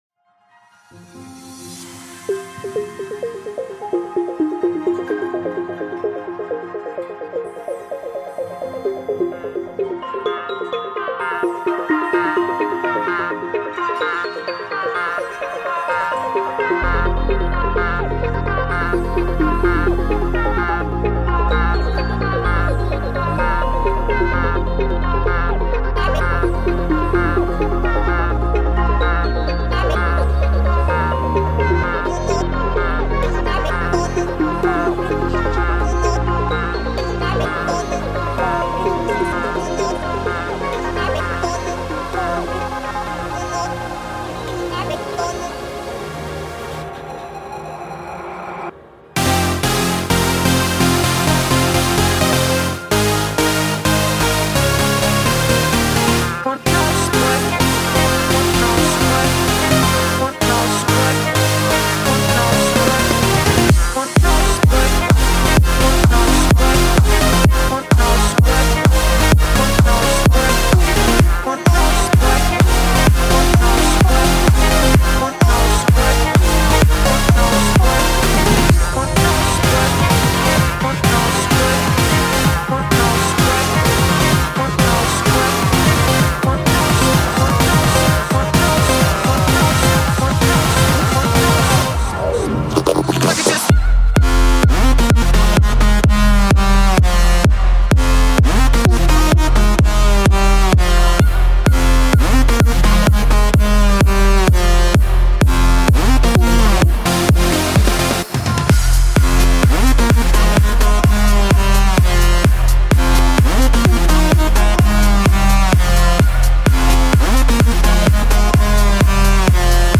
This time it's bigroom all the way!